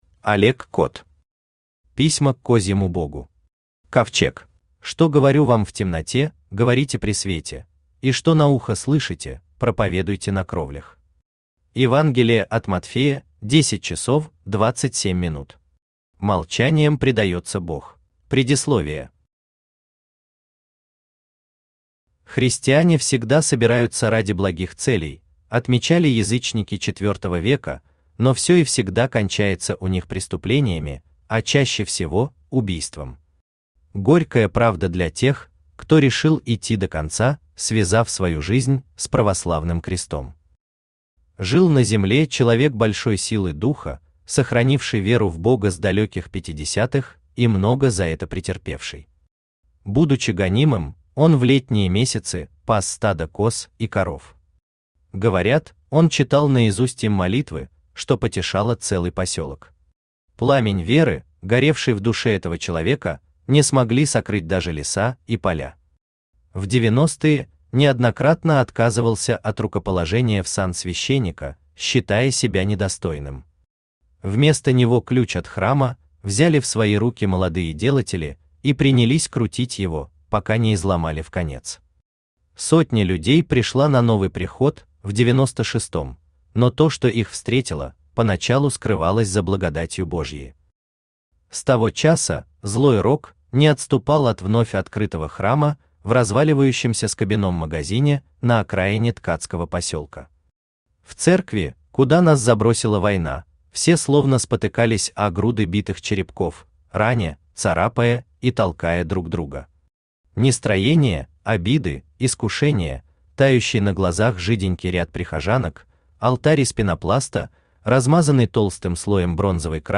Аудиокнига Письма к козьему богу. Ковчег | Библиотека аудиокниг
Aудиокнига Письма к козьему богу. Ковчег Автор Олег Кот Читает аудиокнигу Авточтец ЛитРес.